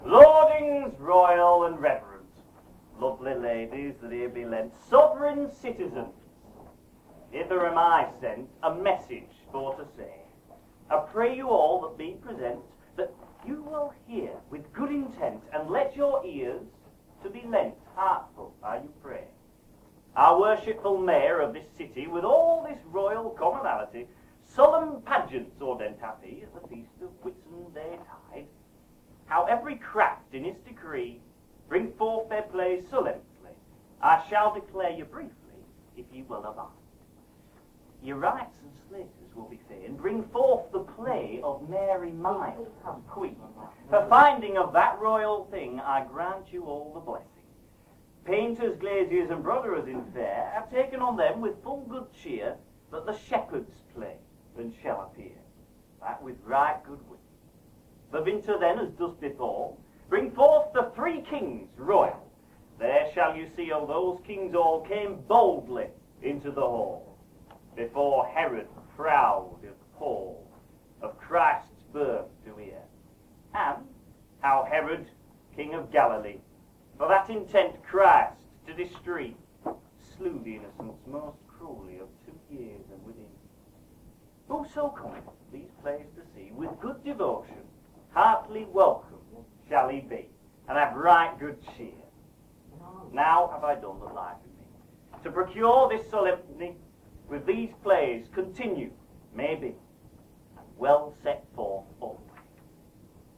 This is a live recording from a selection of mystery plays compiled in 2007 for a show called Medieval Mysteries - focusing on the Nativity. The opening of the show used relevant sections of the Chester Banns - Banns used to announce the coming mystery pageants before the reformation. Audio quality is very poor - it was recorded with a mini-disc player using headphones as a microphone. It's a miracle, frankly, it sounds this good.